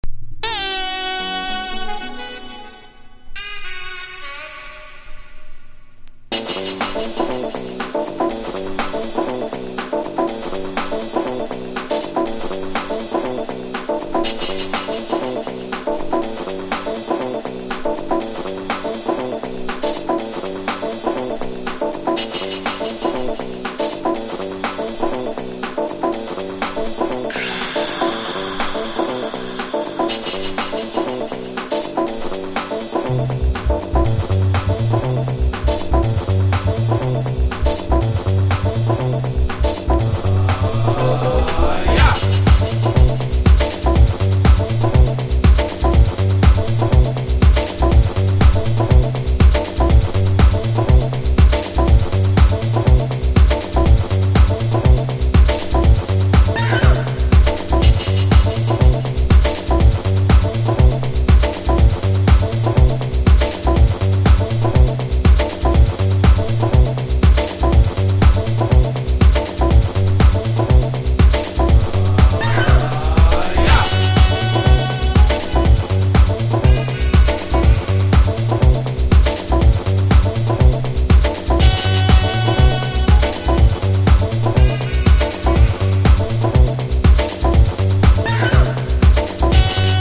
歌ものクラブ･ジャズ作品。ラウンジ、ハウス、ジャズなど、様々ダンス･ミュージックやチル･アウト･サウンドを聴かせます。